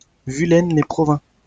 来自 Lingua Libre 项目的发音音频文件。 语言 InfoField 法语 拼写 InfoField Vulaines-lès-Provins 日期 2019年2月6日 来源 自己的作品